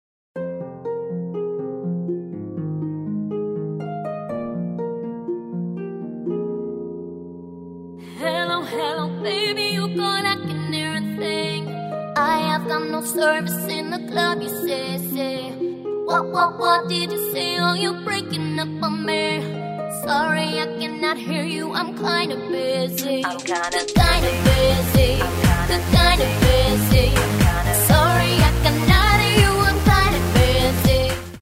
and LIVE diva vocals